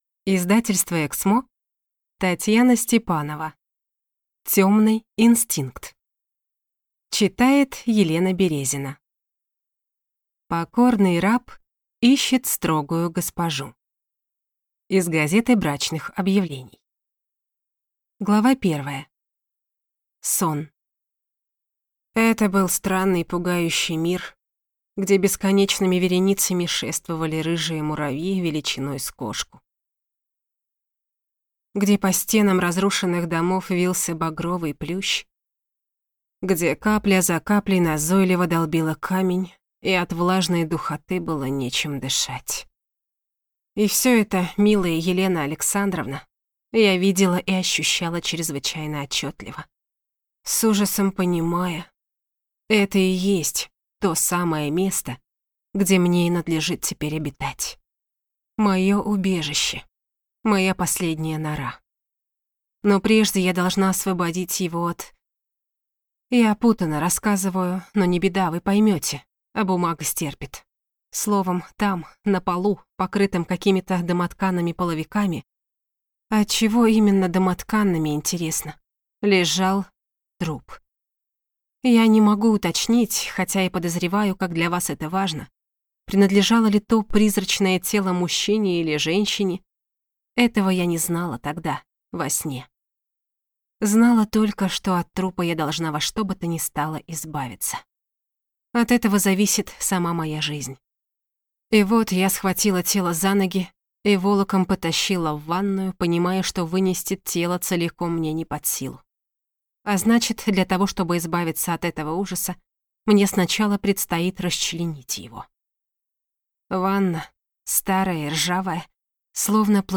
Аудиокнига Темный инстинкт | Библиотека аудиокниг